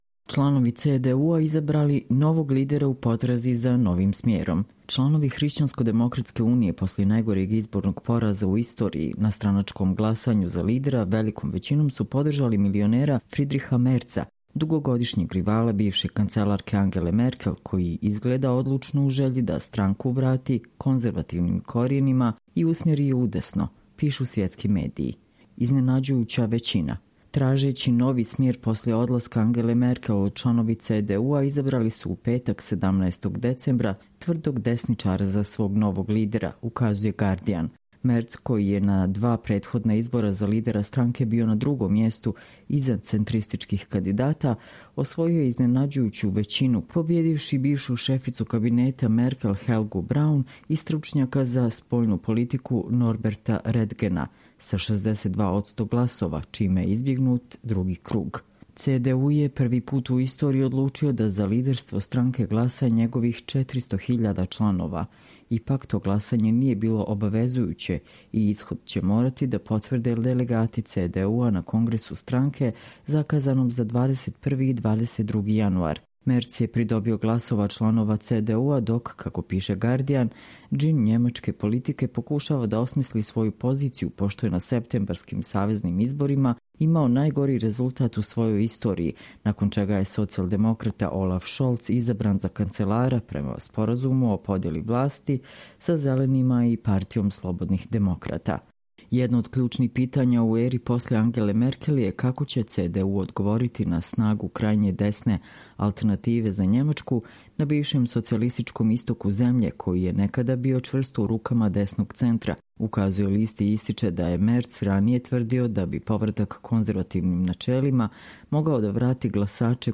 Čitamo vam: Članovi CDU-a izabrali novog lidera u potrazi za novim smerom